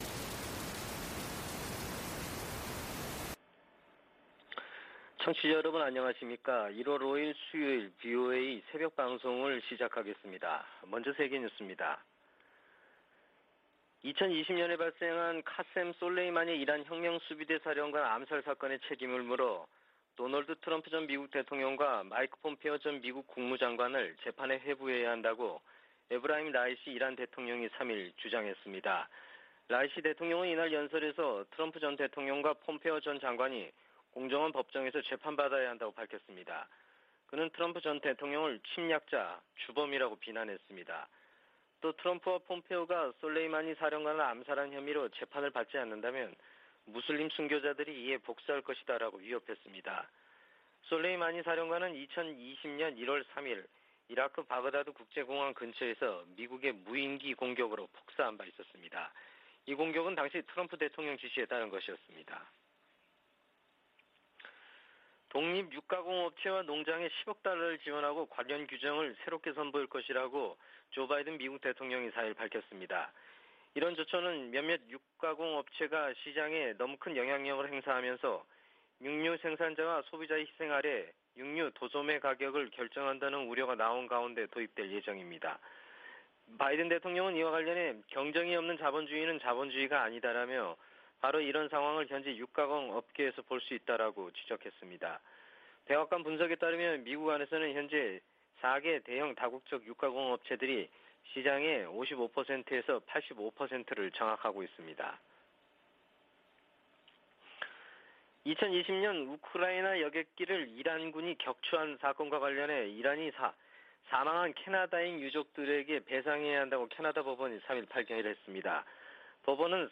VOA 한국어 '출발 뉴스 쇼', 2021년 1월 5일 방송입니다. 미 국무부 동아시아태평양국이 조 바이든 행정부 들어 대북 외교에서 역할을 복원 중이라는 감사보고서가 나왔습니다. 미국 정부가 북한에 대화 복귀를 거듭 촉구했습니다. 주요 핵무기 보유국들이 핵무기 사용에 반대하고, 핵확산금지조약(NPT)의 의무를 강조하는 공동성명을 발표했습니다.